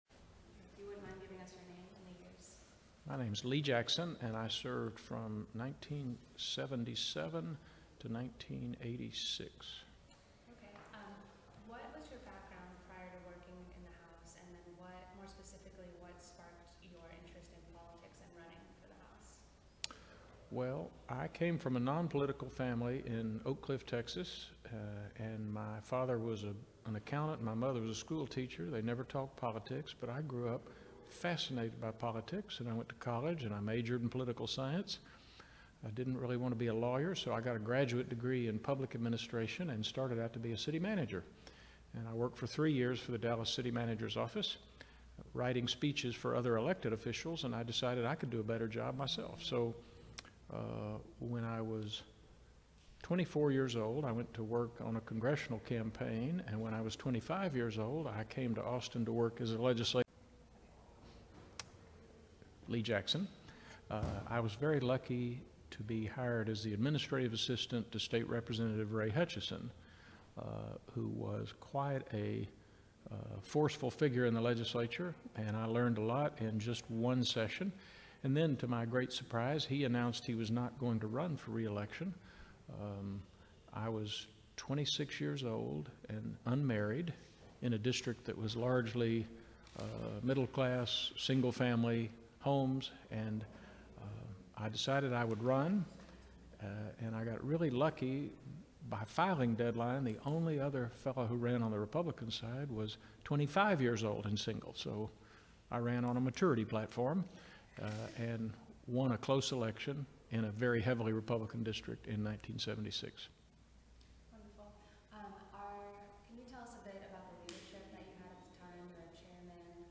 Oral history interview with Lee Jackson, 2015. Texas House of Representatives .